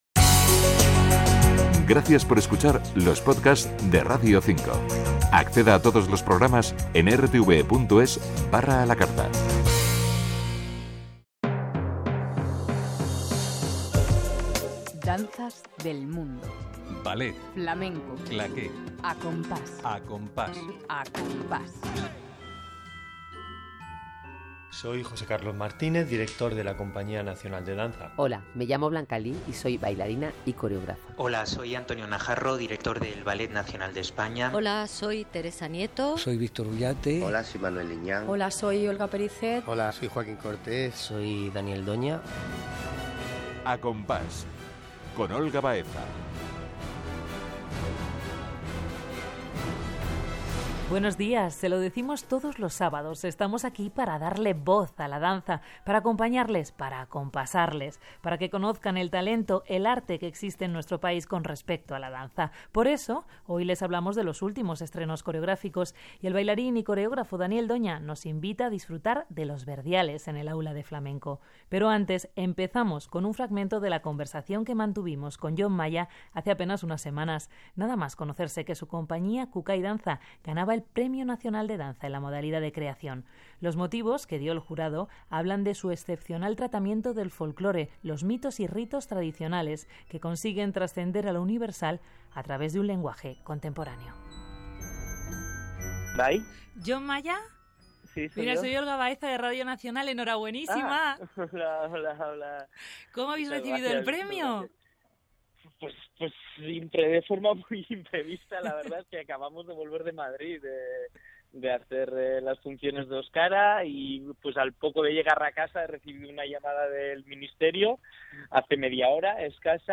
elkarrizketa RTVEko "A compás" saioan